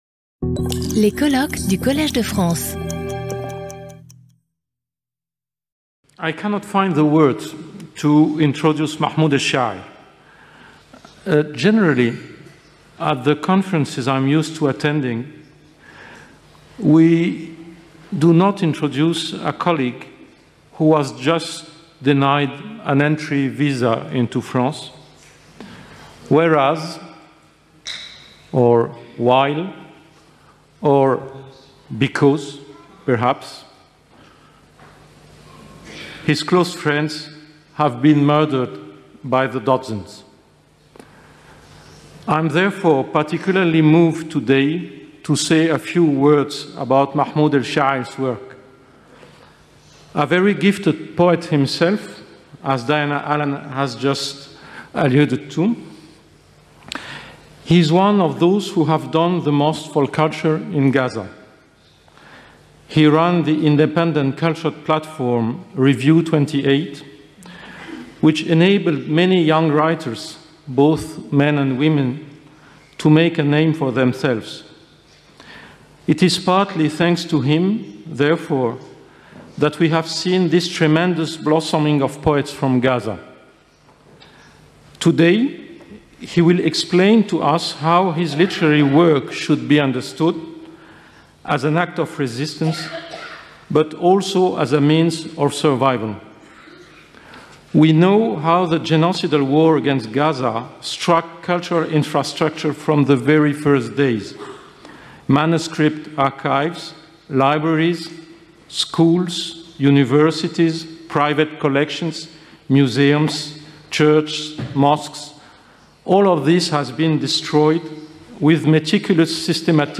Lecture audio
Sauter le player vidéo Youtube Écouter l'audio Télécharger l'audio Lecture audio Cette vidéo est proposée dans une version doublée en français.